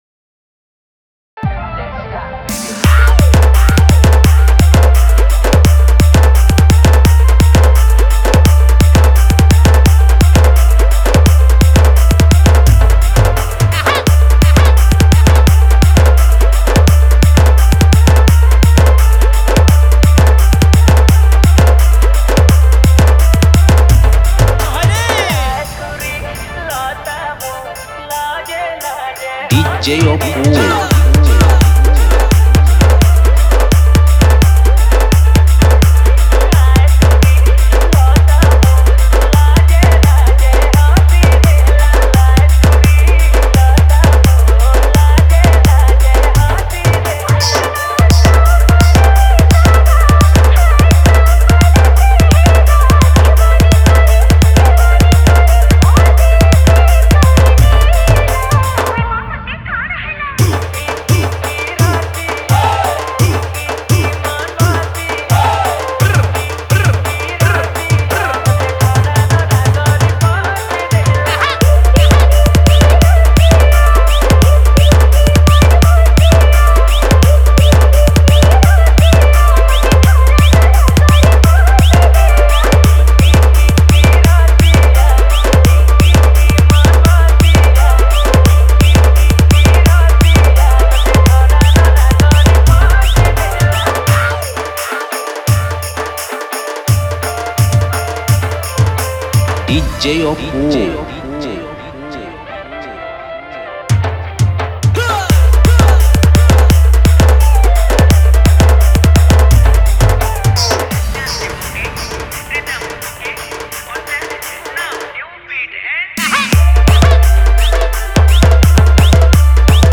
Sambalpuri Dance Mix